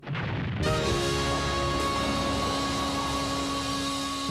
Category: Anime Soundboard.
The deden sound effect is widely used in meme videos, Instagram Reels, YouTube Shorts, gaming clips, and funny viral content.